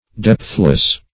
depthless - definition of depthless - synonyms, pronunciation, spelling from Free Dictionary
Depthless \Depth"less\, a.